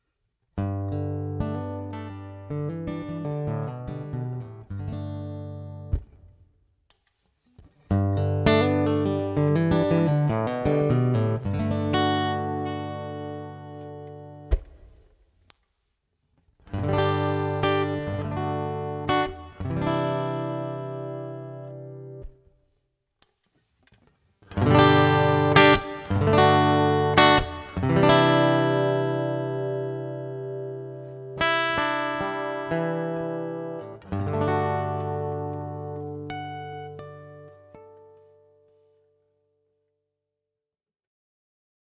Acoustic preamp - fingers/pick